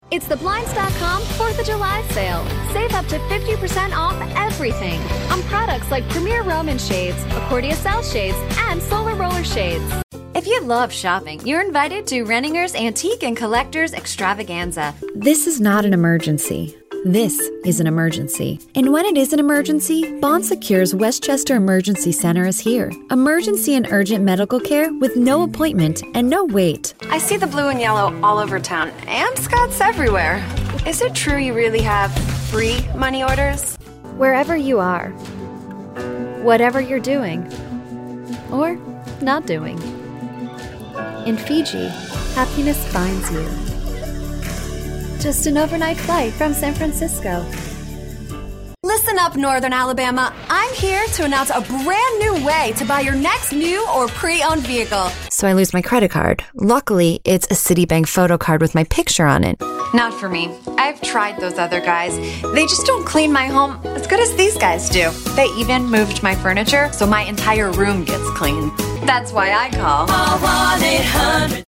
Teenager, Adult, Young Adult
Accents: standard us
GENRE: explainer video VOICE CHARACTERISTICS: friendly warm well spoken
CommercialDemo2018.mp3